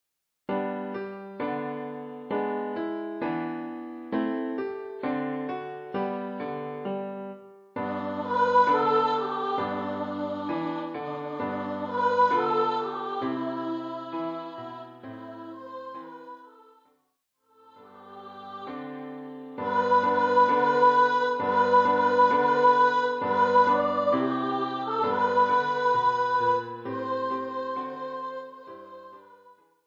für Gesang, hohe Stimme